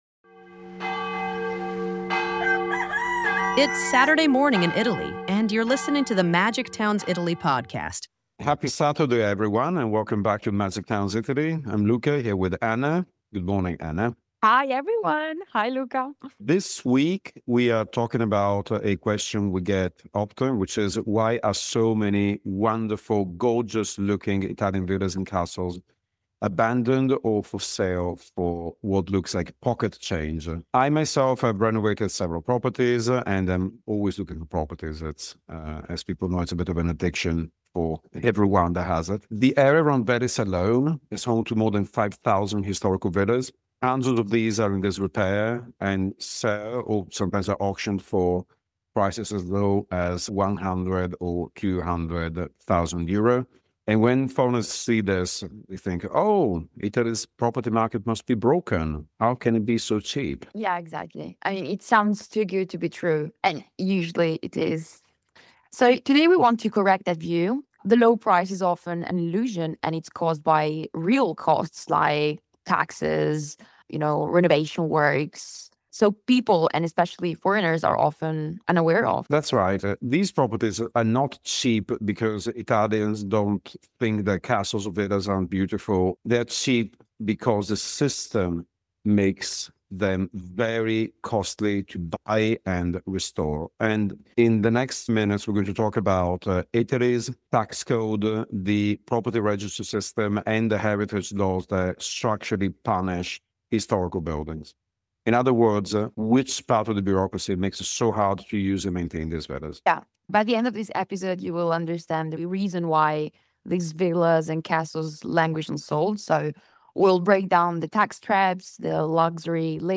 Magic Towns Italy Property in Italy Traps: An Interview With Realtor